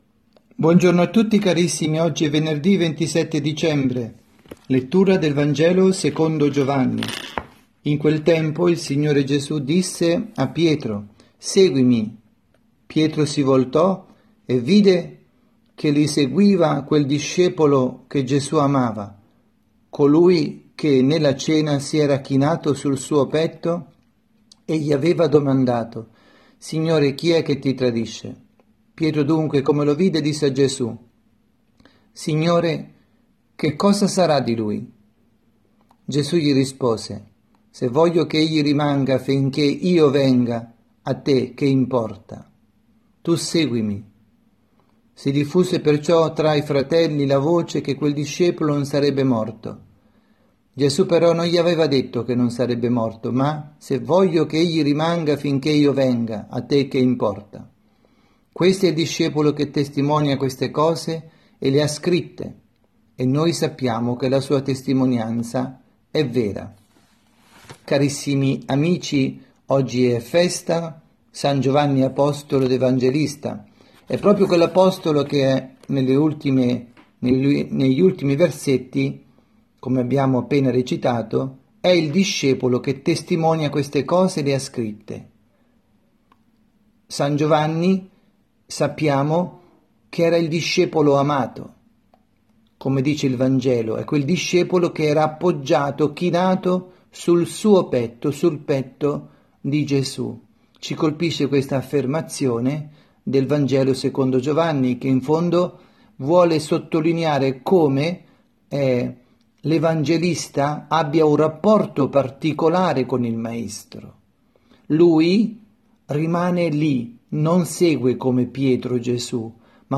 avvisi, Omelie
2019-12-27_Venerdi_pMG_Catechesi_dalla_Parrocchia_S_Rita_Milano.mp3